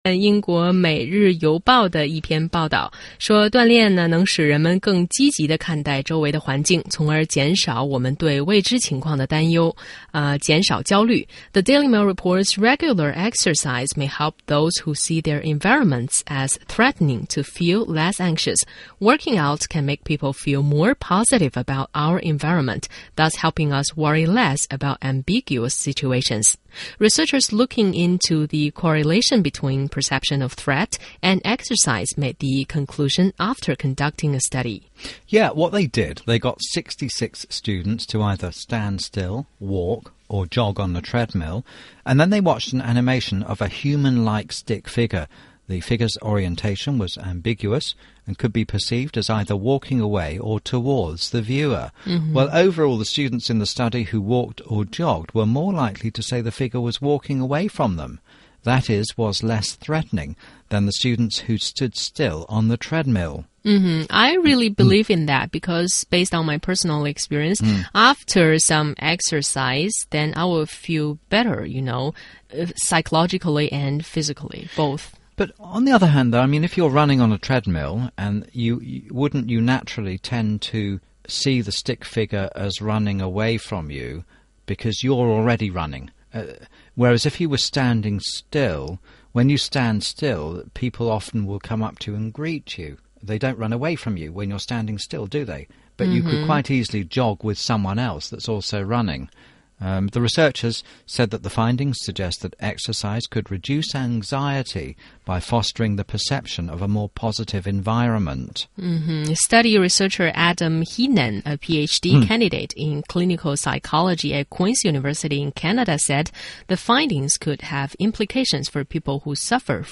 中英双语的音频，能够帮助提高英语学习者的英语听说水平，中外主持人的地道发音，是可供模仿的最好的英语学习材料,可以帮助英语学习者在轻松娱乐的氛围中逐渐提高英语学习水平。